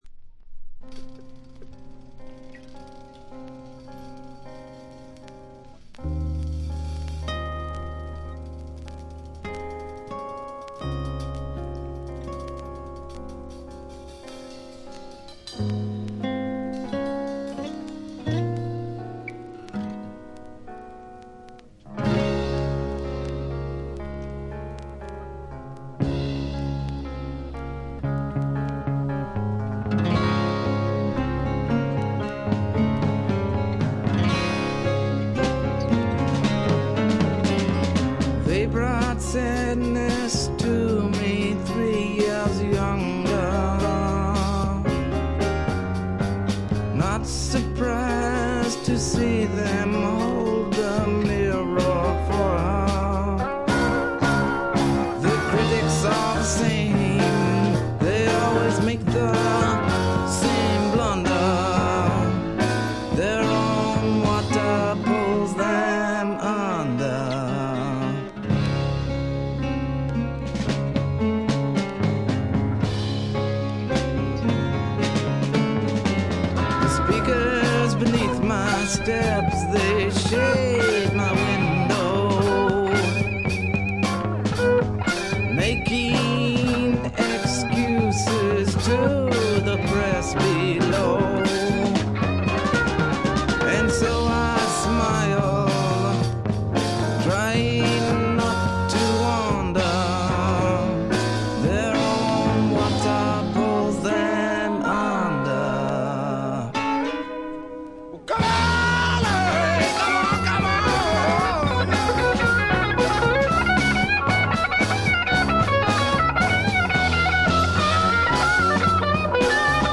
ところどころで軽いプツ音、チリプチが出ますがほとんど気にならないレベルで良好に鑑賞できると思います。
試聴曲は現品からの取り込み音源です。